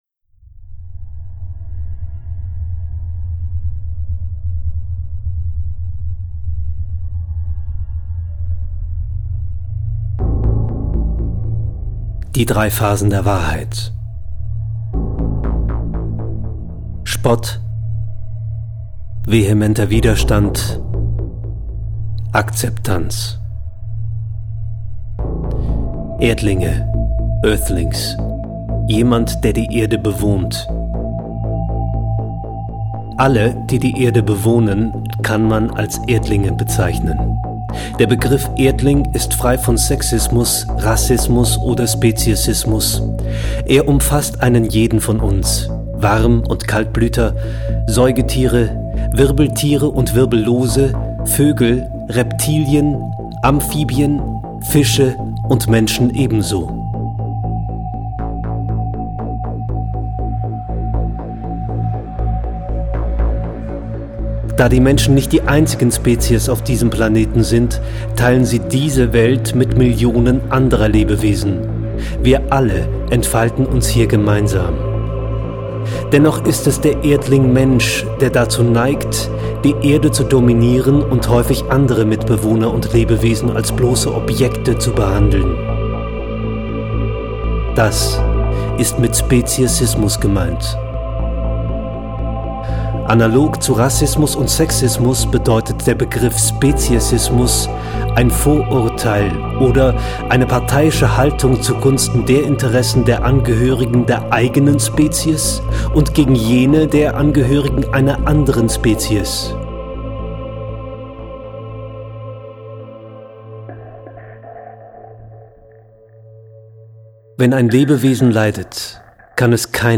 Erdlinge - Audiodokumentation
Im Jahre 2008 habe ich zusammen mit einem Bekannten die Audioversion von "Earthlings" auf deutsch produziert.
Erdlinge_AudiodokuPt1.MP3